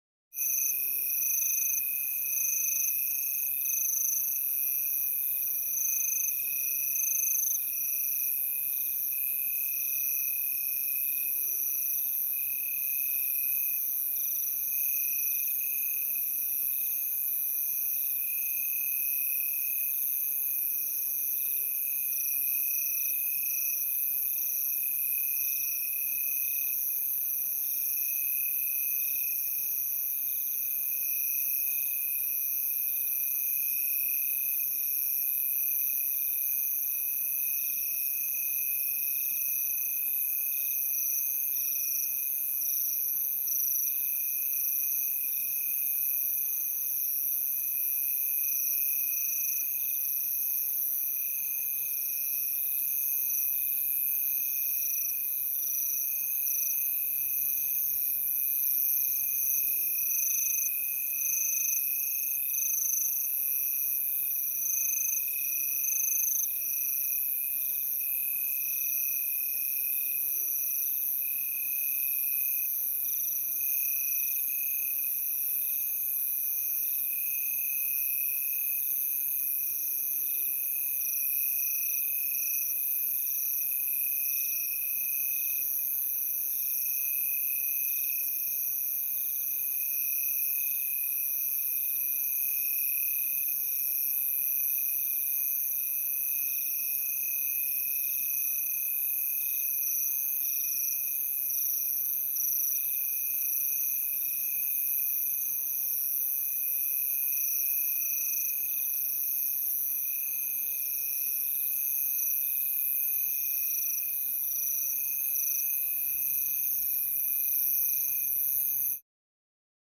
جلوه های صوتی
دانلود صدای جیرجیرک 1 از ساعد نیوز با لینک مستقیم و کیفیت بالا